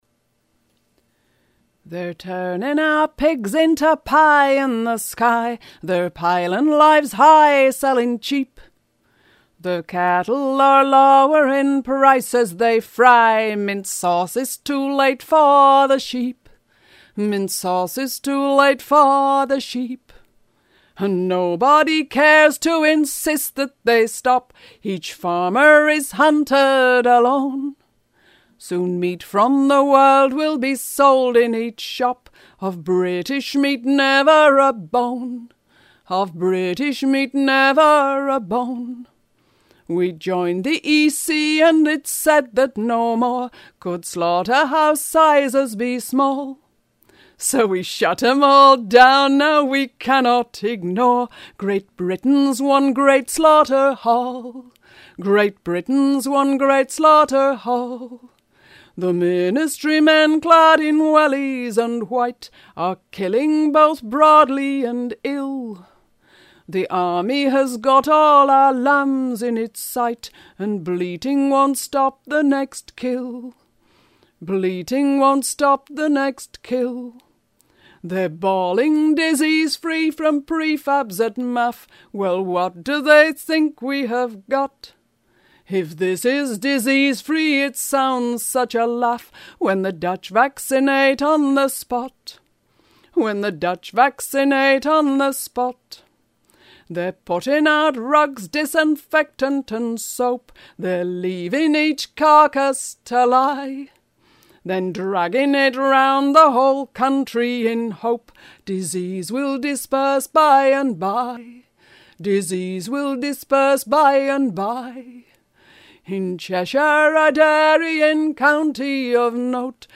foot and mouth protest songUK Foot-and-Mouth Slaughter - Protest Song: Audiofile (3.8 Mb)   Lyrics